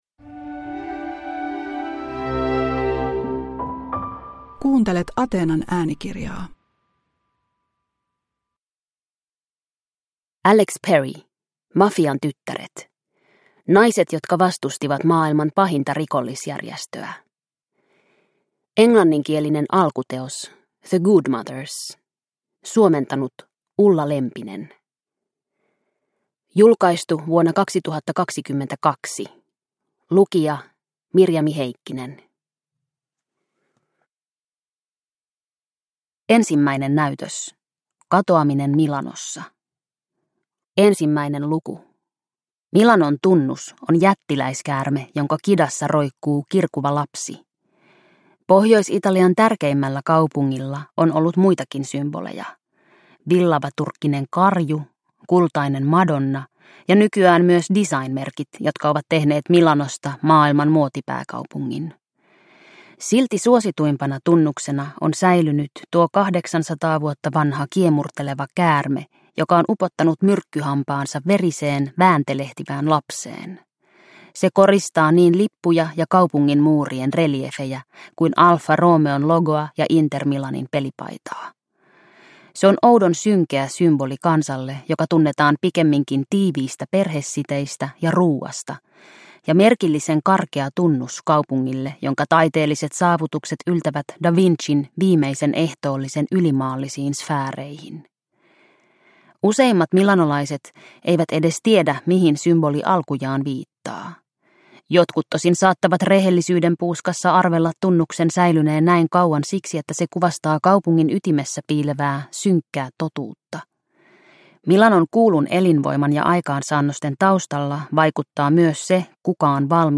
Mafian tyttäret – Ljudbok – Laddas ner